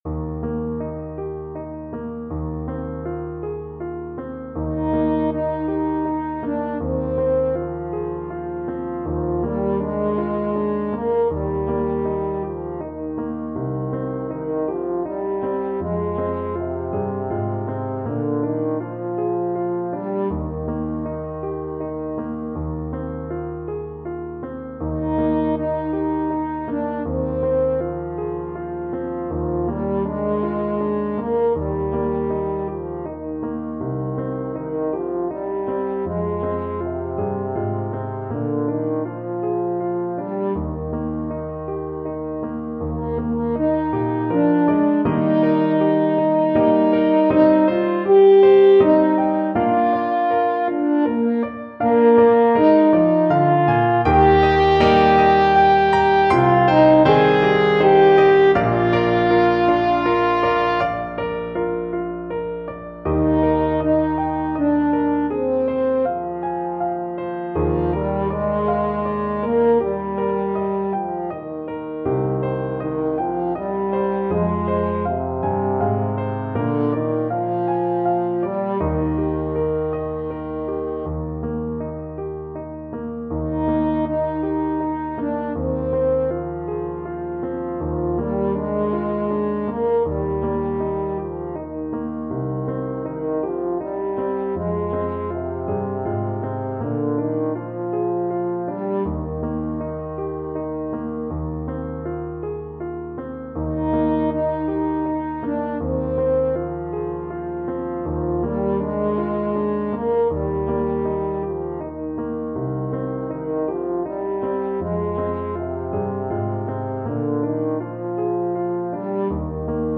French Horn
Traditional Music of unknown author.
Eb major (Sounding Pitch) Bb major (French Horn in F) (View more Eb major Music for French Horn )
Gently flowing =c.80
3/4 (View more 3/4 Music)